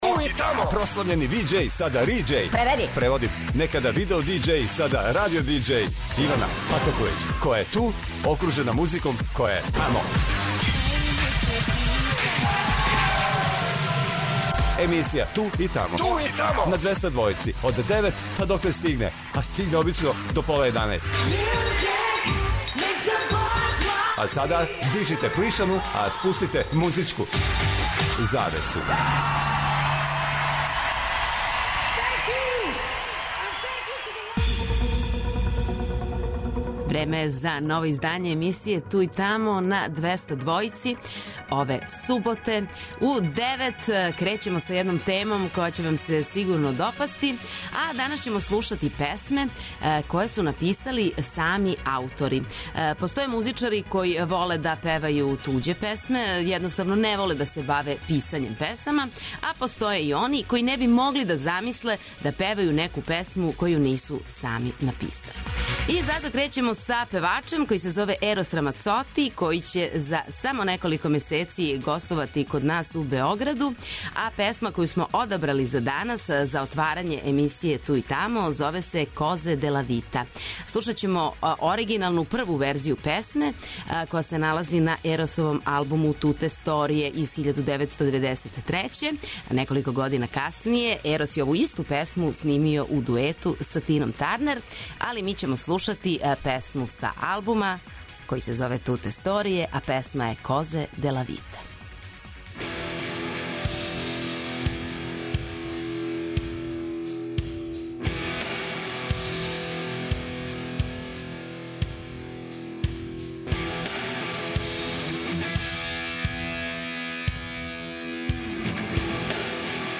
Емисија Ту и тамо суботом од 9 ујутро доноси нове, занимљиве и распеване музичке теме. Док се будите и пијете јутарњу кафу обавезно појачајте 'Двестадвојку' јер вас очекују велики хитови страни и домаћи, стари и нови, супер сарадње, песме из филмова, дуети и још много тога.